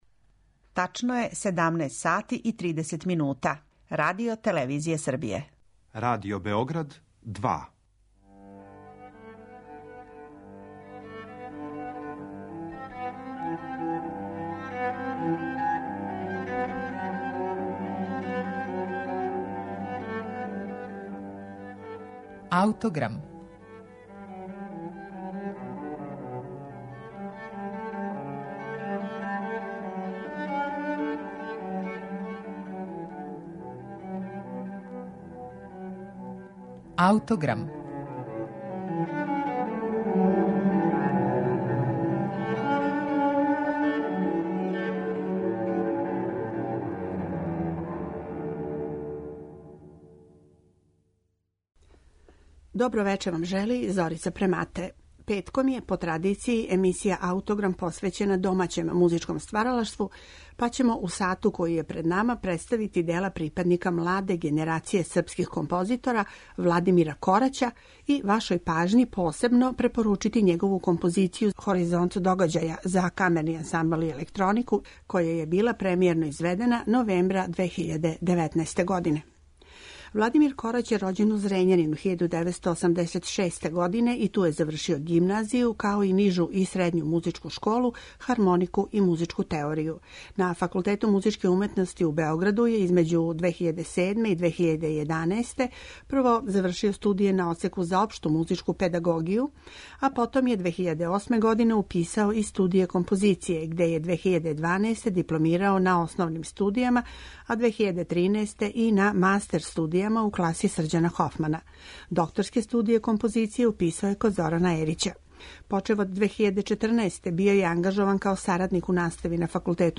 за камерни ансамбл и електронику